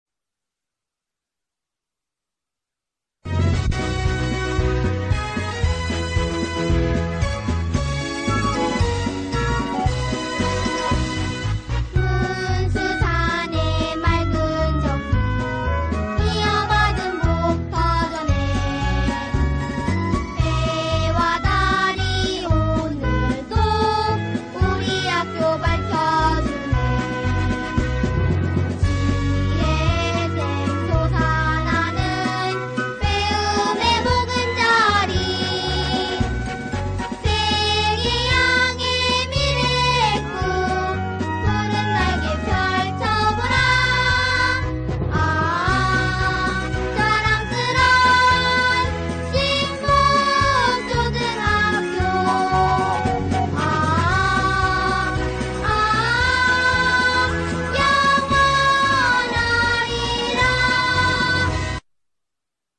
신복초등학교 교가 음원 :미추홀시민아카이브
신복초등학교의 교가 음원으로 정용원 작사, 우덕상 작곡이다.